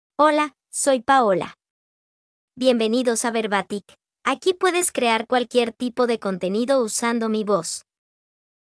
FemaleSpanish (Venezuela)
Paola is a female AI voice for Spanish (Venezuela).
Voice sample
Listen to Paola's female Spanish voice.
Female
Paola delivers clear pronunciation with authentic Venezuela Spanish intonation, making your content sound professionally produced.